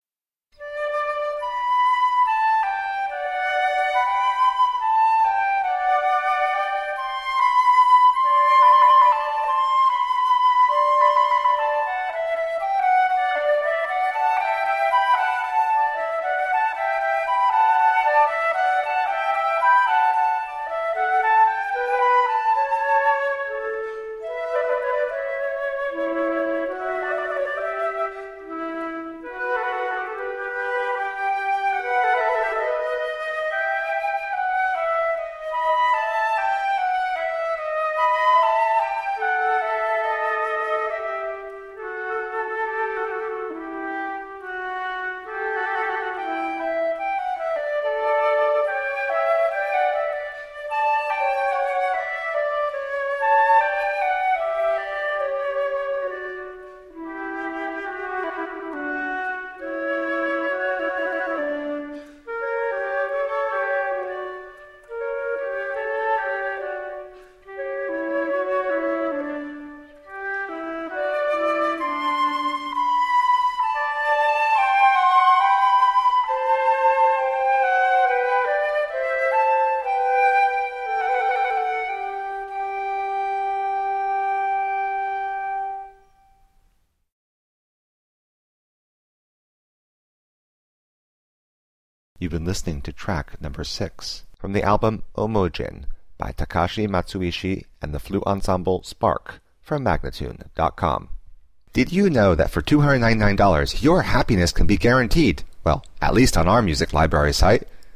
Classical, Baroque, Instrumental
Flute